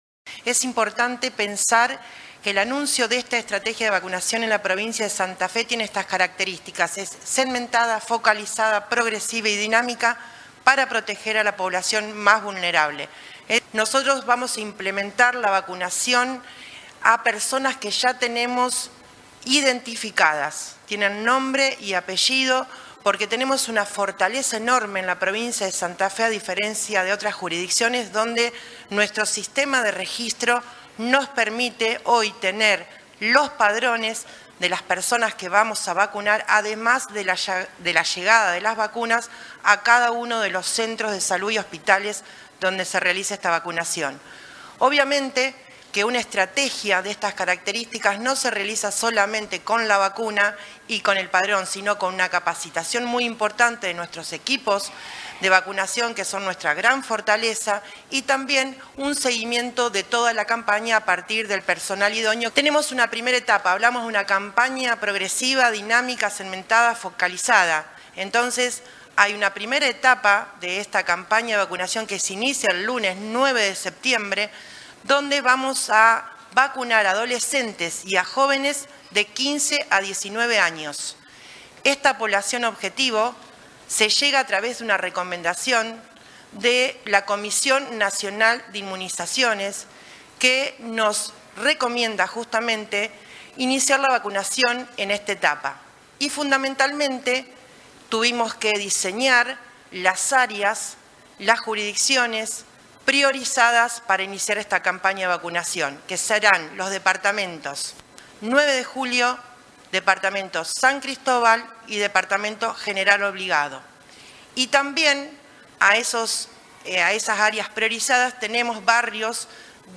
En una conferencia de prensa desarrollada en Casa de Gobierno, en la ciudad de Santa Fe, las autoridades brindaron detalles de la estrategia de vacunación que se realizará en el marco del programa Objetivo Dengue, cuyo eje es la prevención y concientización, y a través del cual el Gobierno provincial adquirió 160 mil dosis (80.000 esquemas completos) de vacunas contra el dengue Qdenga, del laboratorio Takeda Argentina SA, con una inversión de 4.500 millones de pesos.
Declaraciones de Ciancio y Cunha